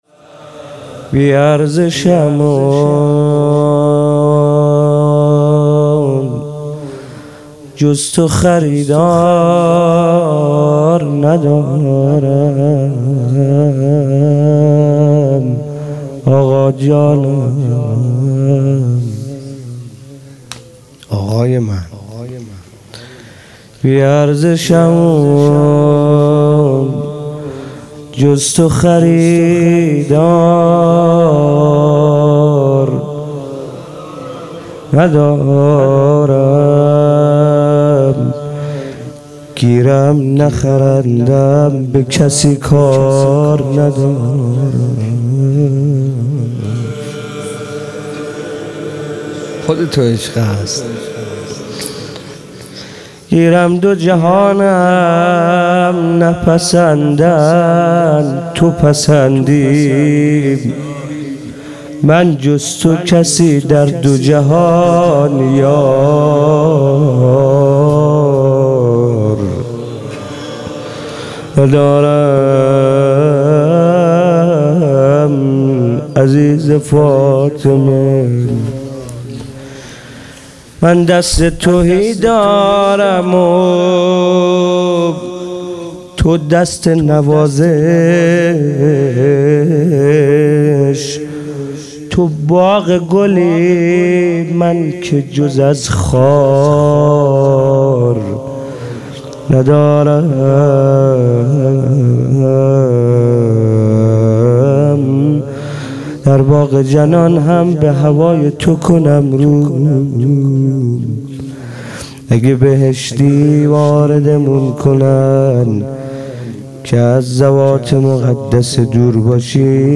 هفتگی 20 آذر 97 - مناجات با امام زمان علیه السلام